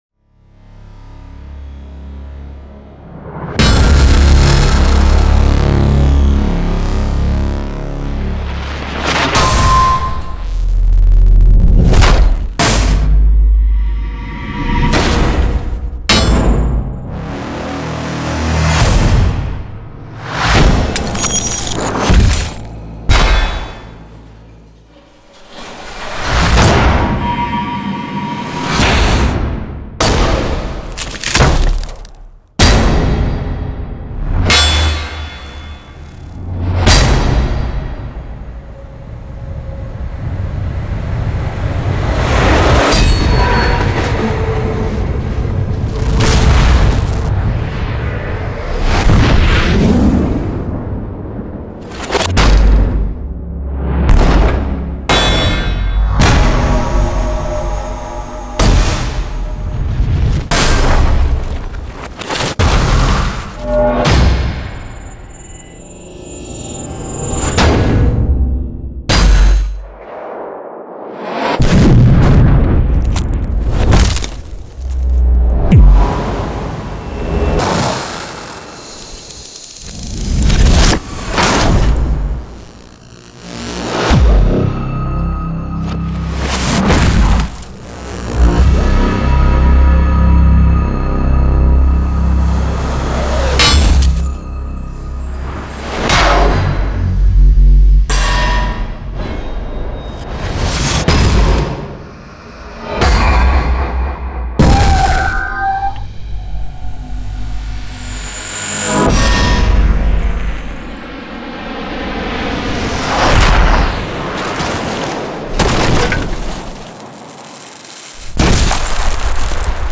音效素材-179种重金属合成器打击电影冲击音效素材
这个可下载的样本包具有179种精心设计的音效集合，涵盖了各种主题，包括重金属滴，音调合成器打击，火车和喷气式飞机过客，扭曲的电影风格冲击，电crack啪声，木头和玻璃碎片，铁砧撞击，炸弹爆炸，飞旋以及其他更多功能。